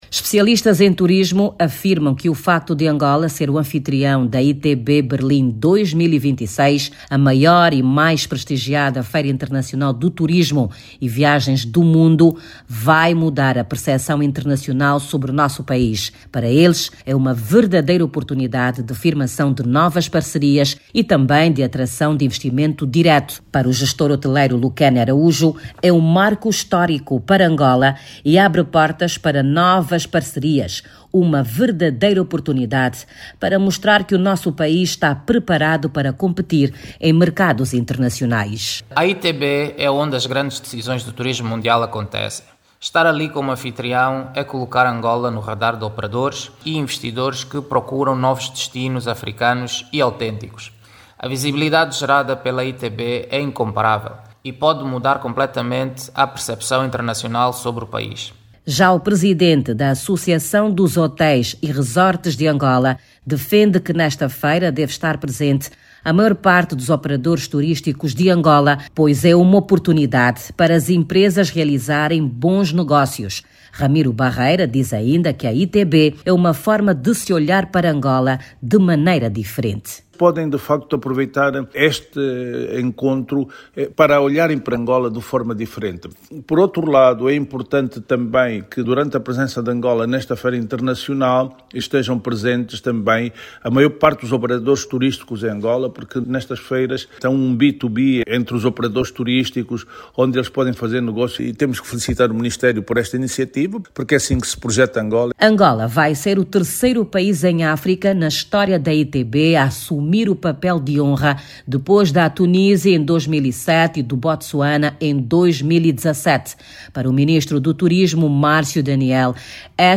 A partir de Berlim jornalista